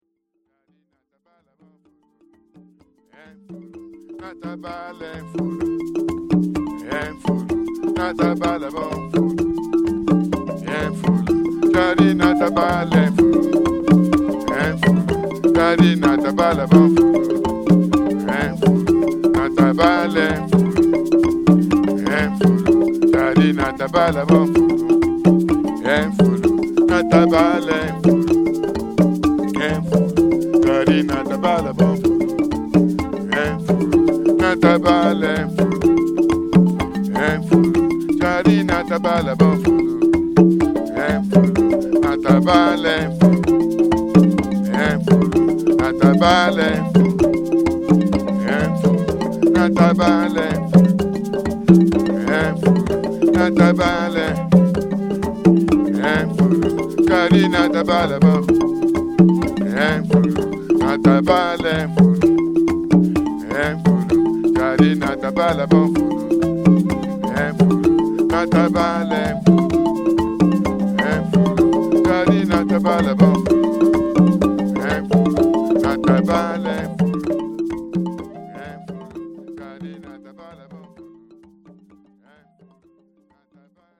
African-inspired